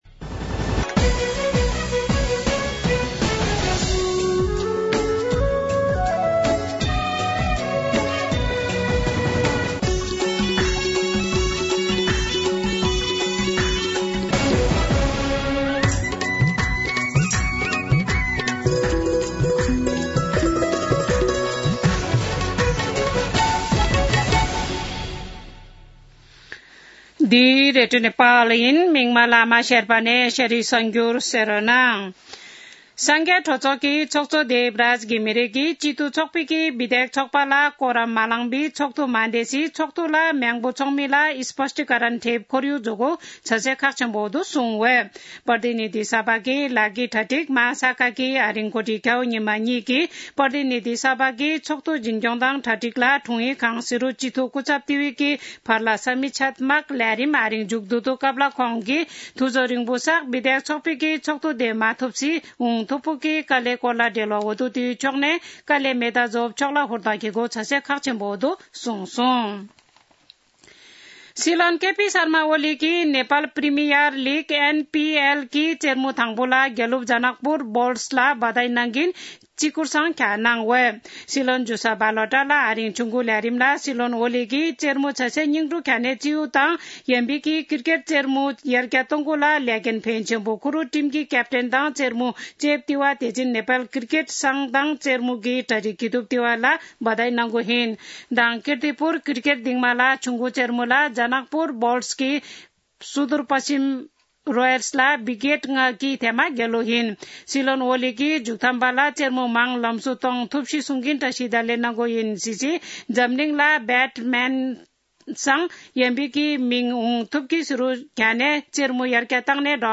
शेर्पा भाषाको समाचार : ८ पुष , २०८१
Sherpa-news-3.mp3